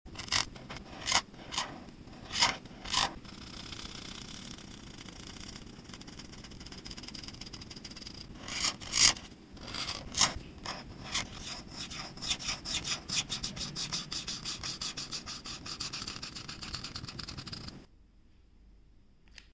ASMR Colors: Coloring a Water sound effects free download
Watch every smooth glide of color and enjoy the relaxing sounds that make ASMR art so addictive.